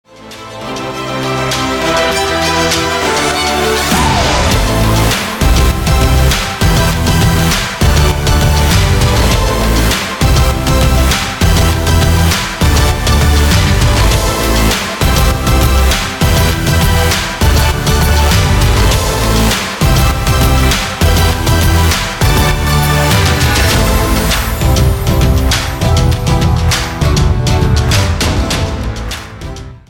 • Качество: 320, Stereo
без слов
инструментальные
мотивационные
Инструментальный мотивационный рингтон